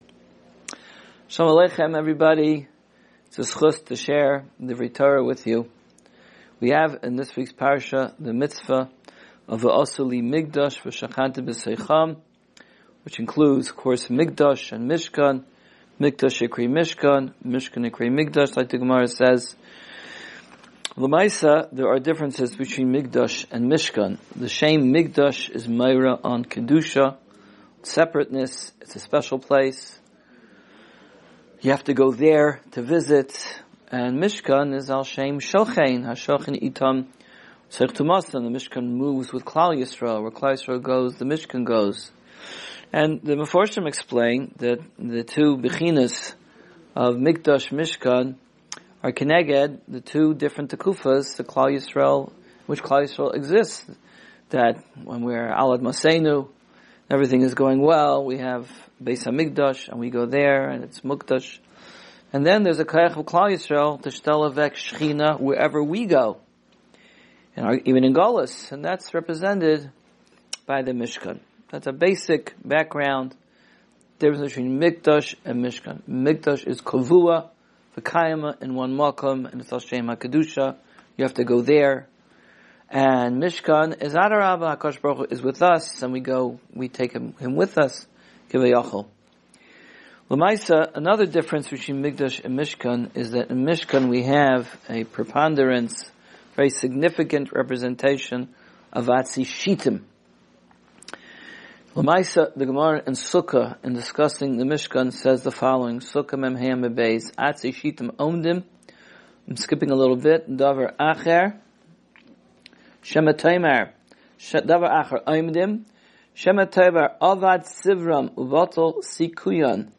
Weekly Alumni Shiur Terumah 5785